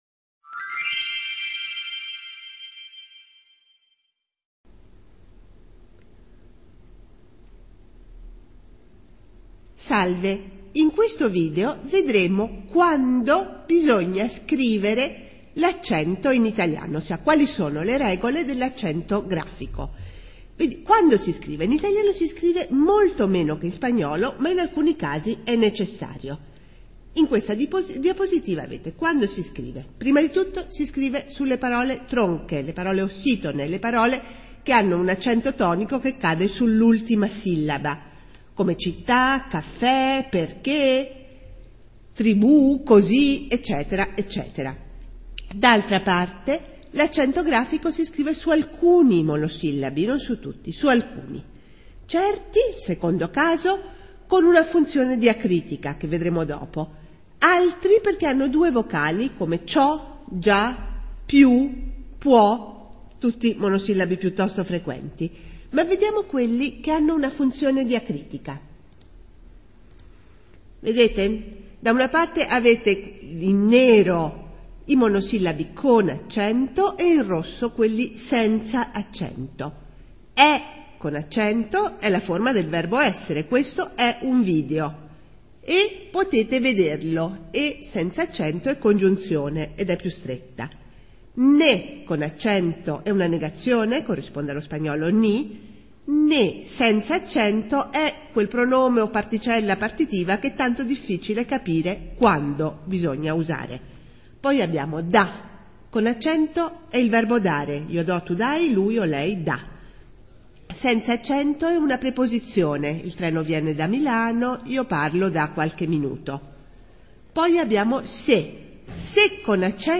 minivídeo docente para Italiano A2
grabación vídeos docentes